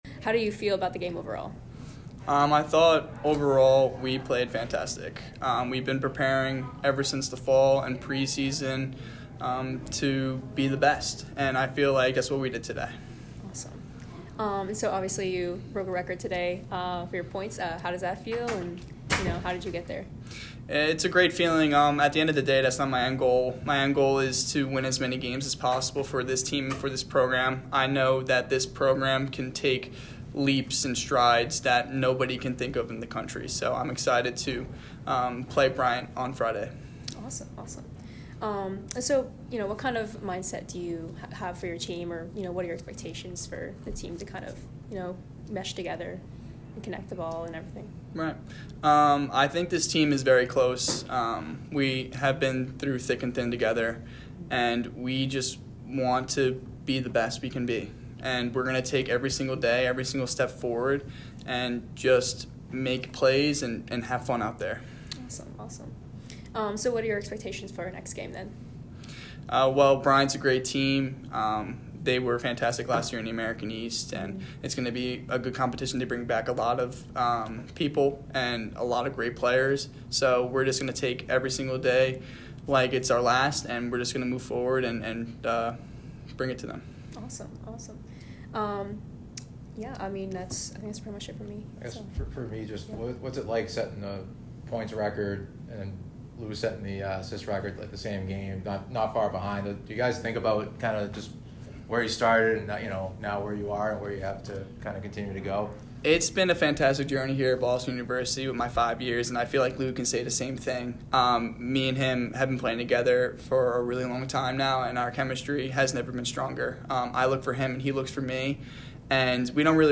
Vermont Postgame Interview